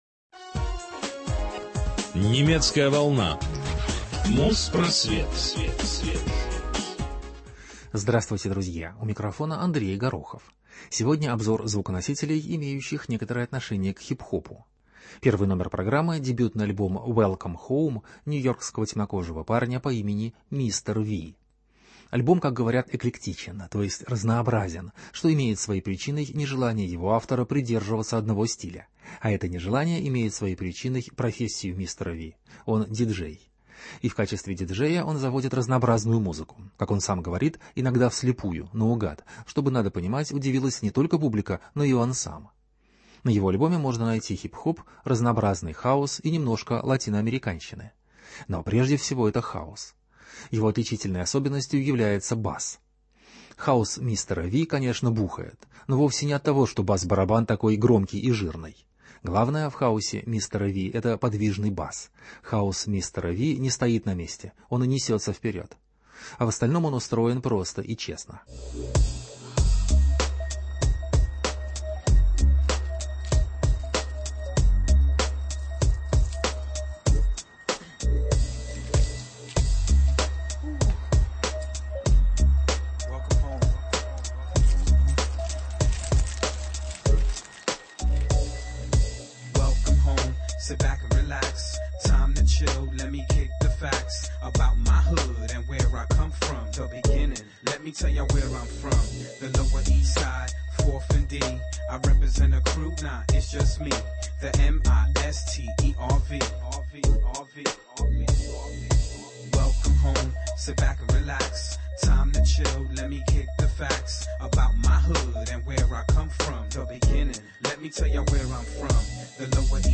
Музпросвет 240 от 17 марта 2007 - Хип-Хоп обзор | Радиоархив
Обзор хип-хопа 2007.